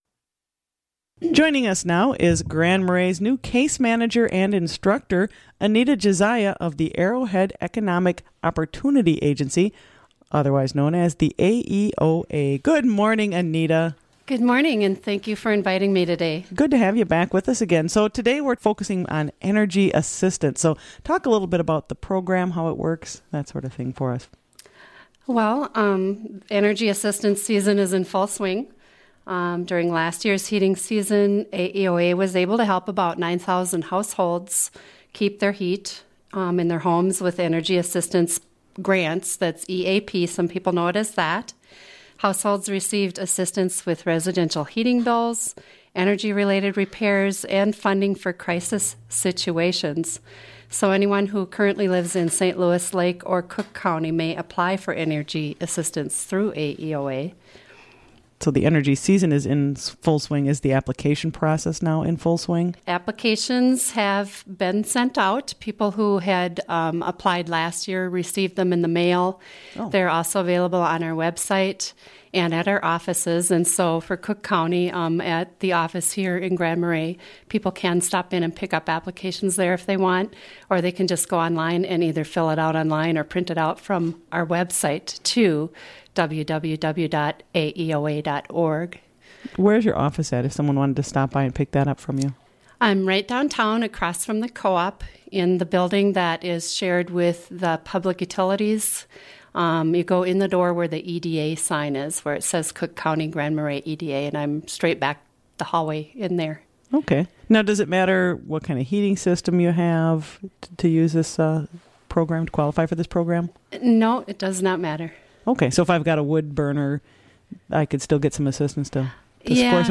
AEOA offers Energy Assistance during winter heating season | WTIP North Shore Community Radio, Cook County, Minnesota